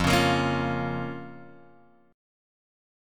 F Suspended 4th Sharp 5th
Fsus4#5 chord {1 4 x 3 2 1} chord